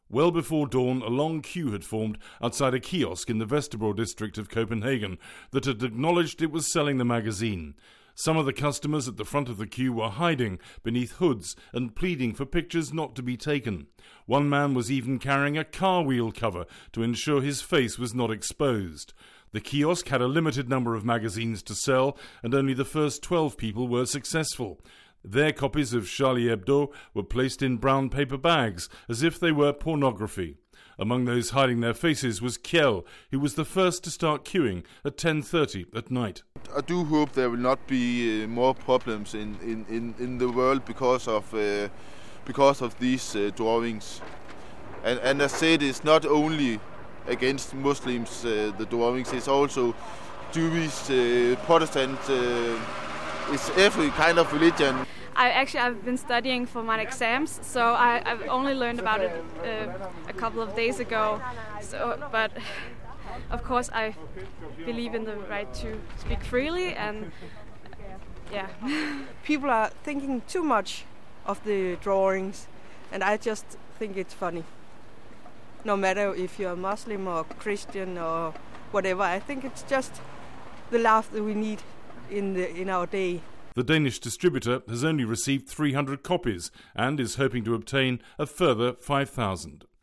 reports from Copenhagen